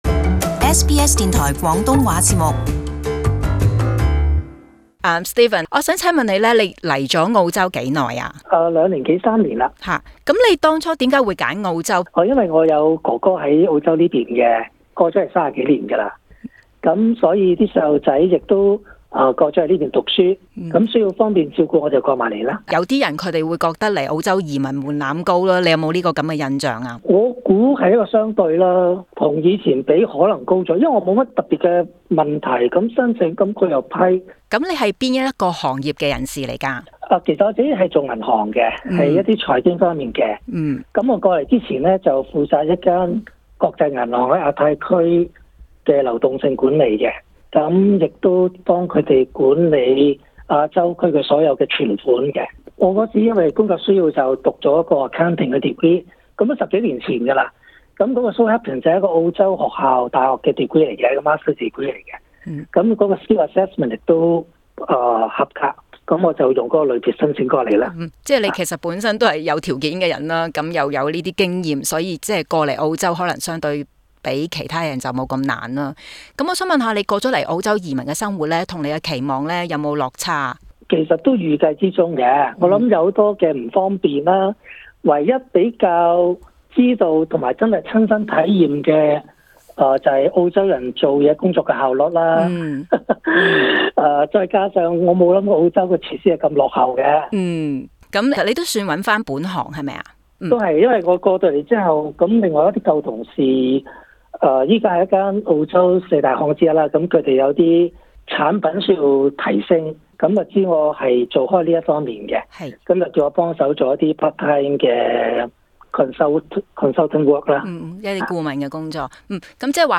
【社區專訪】另一個移民的故事